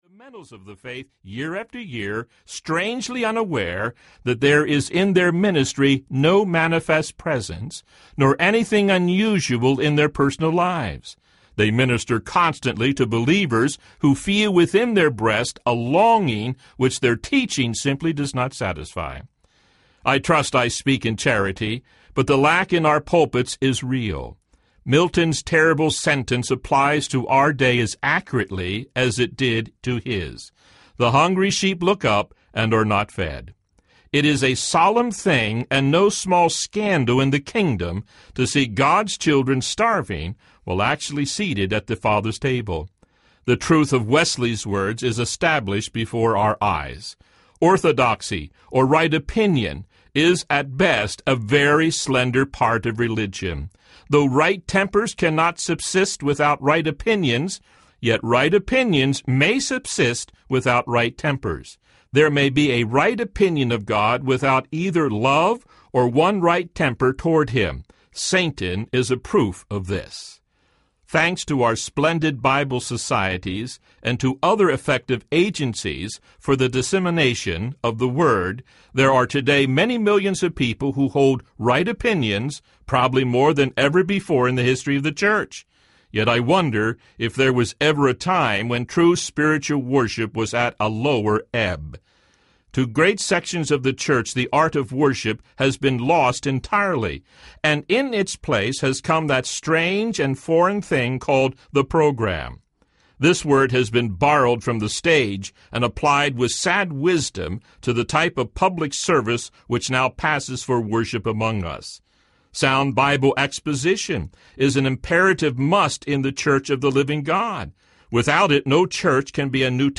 The Pursuit of God (The Definitive Classic) Audiobook
3.9 Hrs. – Unabridged